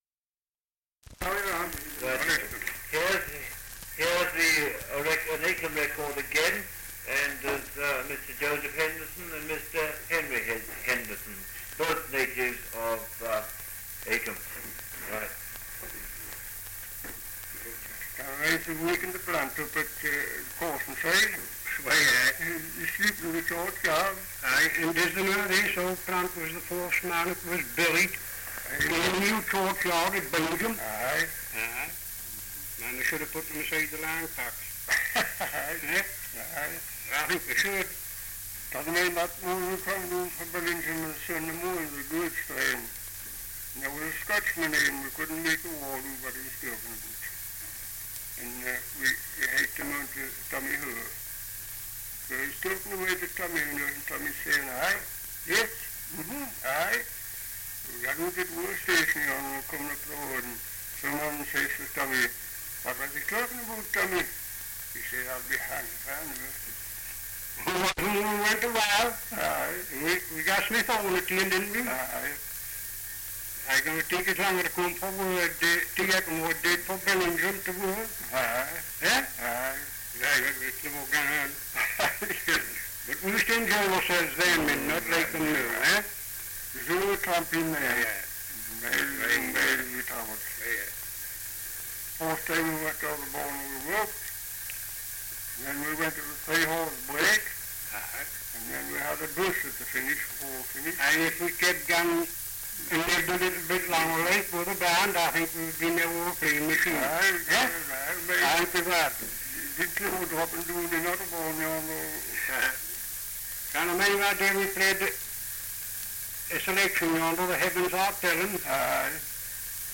1 - Dialect recording in Acomb, Northumberland
78 r.p.m., cellulose nitrate on aluminium
English Language - Dialects